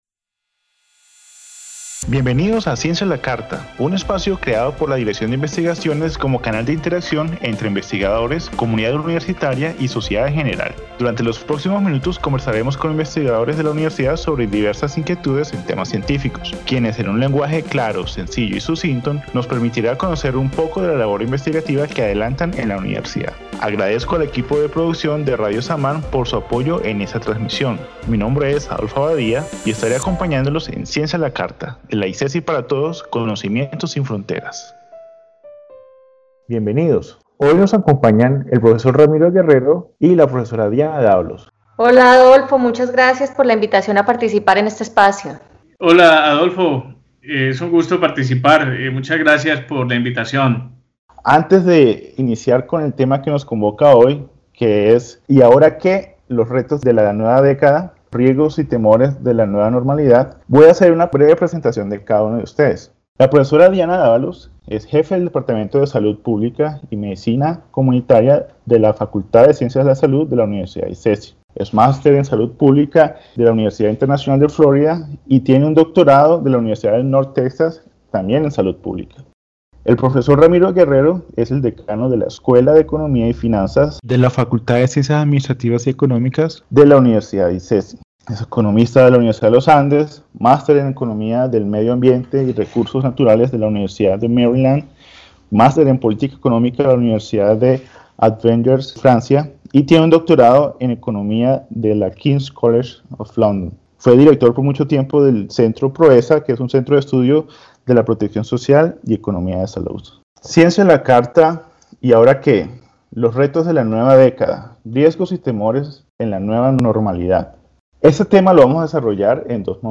En el primer momento del programa, los investigadores invitados tendrán un espacio para reaccionar, libremente, al tema del día y, posteriormente, se traerán a la mesa preguntas formuladas previamente por el público para ser abordadas por medio del diálogo con los expertos invitados.